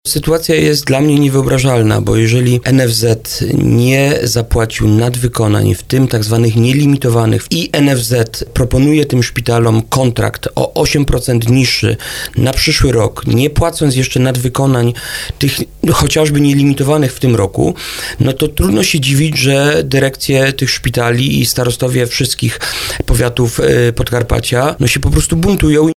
– To niekorzystny kontrakt dla dębickiego szpitala – komentuje wójt gminy Dębica Adam Pieniążek. Gość audycji Słowo za Słowo odniósł się do sytuacji w służbie zdrowia.
Jak mówił na antenie RDN Małopolska, sytuacja ta przybrała charakter walki o lepsze warunki dla szpitali powiatowych.